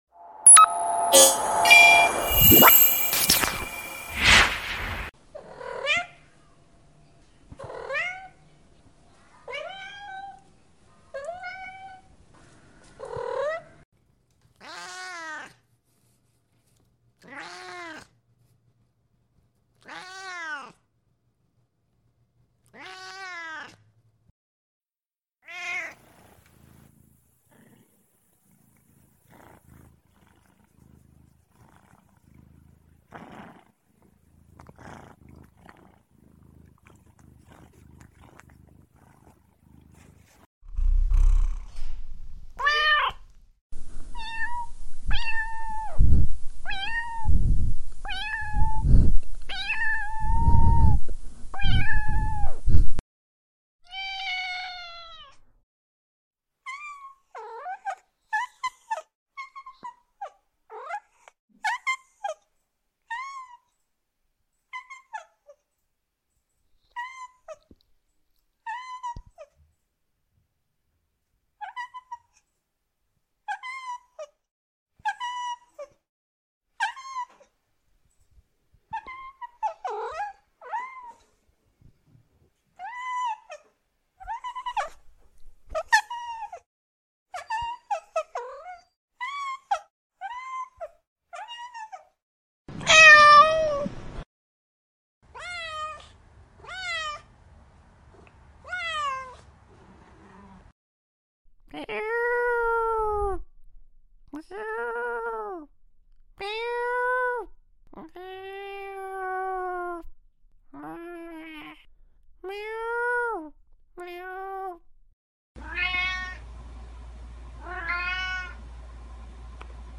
Cat Meow Mash Up Sound sound effects free download